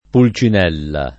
DOP: Dizionario di Ortografia e Pronunzia della lingua italiana
pul©in$lla] (antiq. Polcinella) pers. m. — maschera della commedia dell’arte — anche con p‑ minusc. se usato in sign. fig. (s. m., inv.) — sim. i cogn. Pulcinella, Pulcinelli